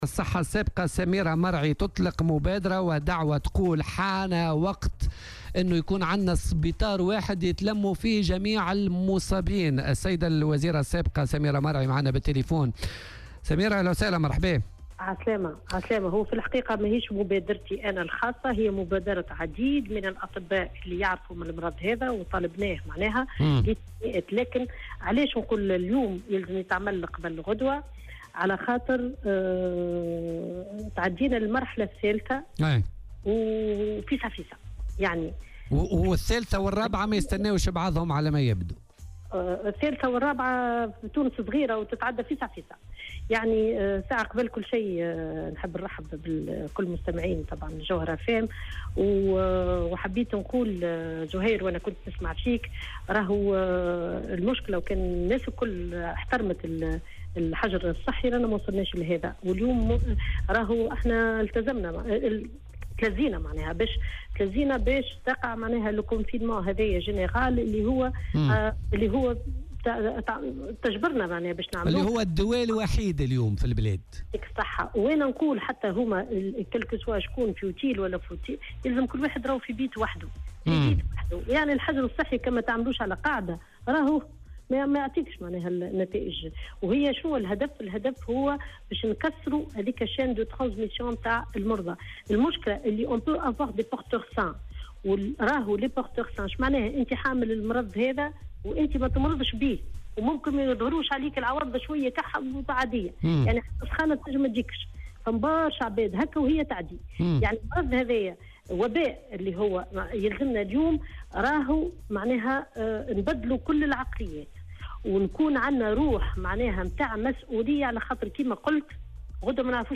وأضافت في مداخلة لها اليوم في برنامج "بوليتيكا" أن تونس مرت إلى المرحلة الثالثة بشكل سريع، في ظل غياب وسائل الحماية ونقص المعدات، متوقعة أن تسجل تونس الكبرى عددا كبيرا من الإصابات، وفق قولها.